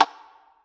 spinwheel_tick_02.ogg